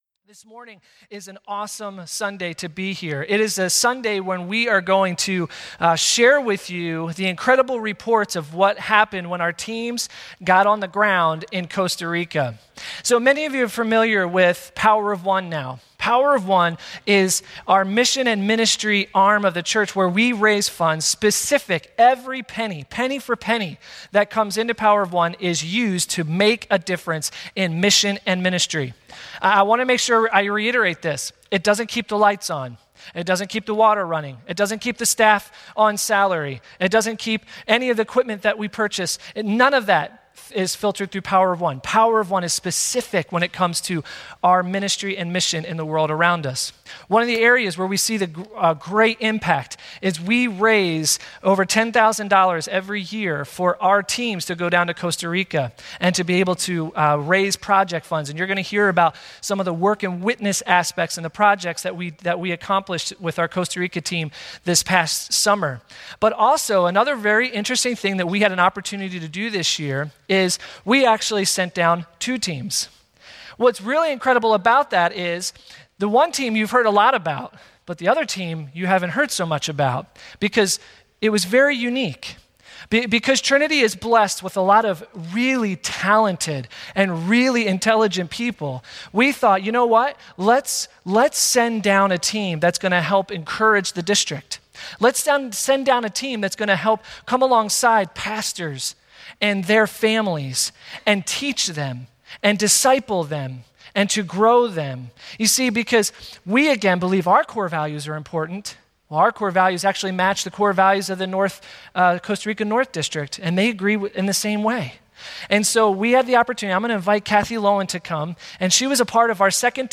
Two team reports from trips to the Costa Rica North district.